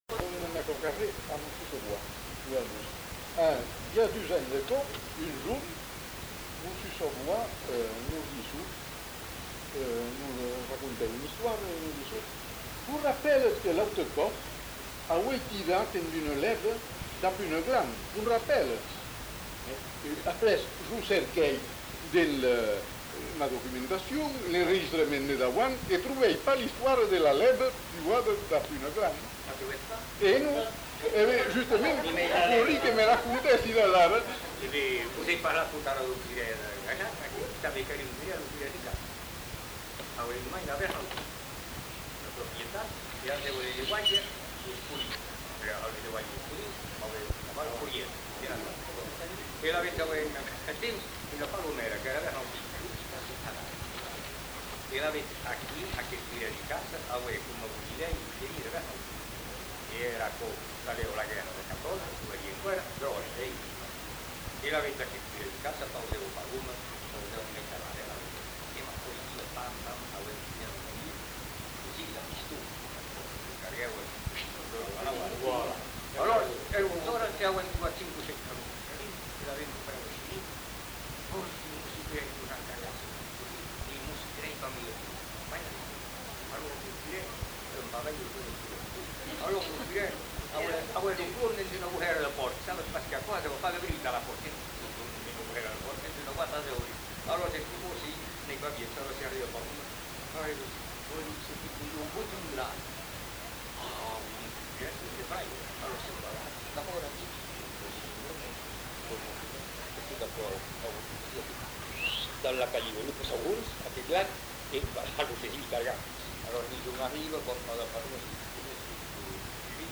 Aire culturelle : Bazadais
Lieu : Bazas
Genre : conte-légende-récit
Type de voix : voix d'homme
Production du son : parlé
Notes consultables : Inaudible. Suivi d'un autre récit non identifié car inaudible.